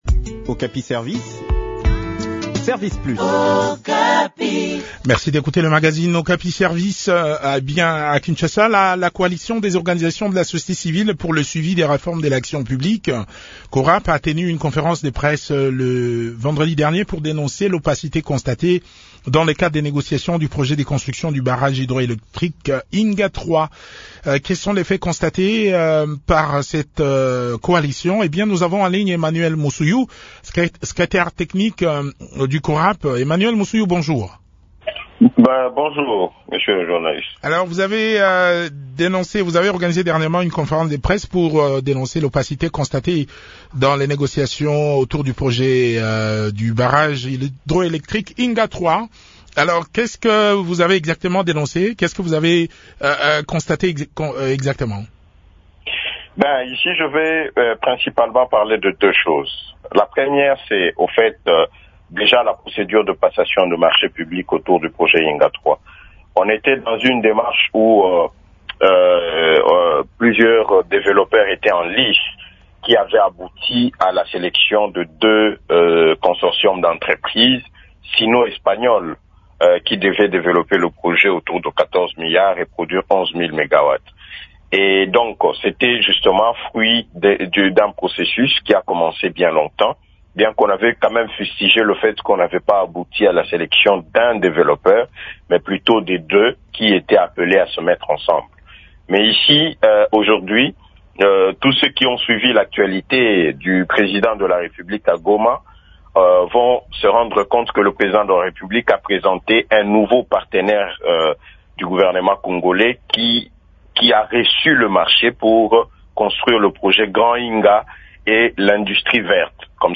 s’entretient sur ce sujet avec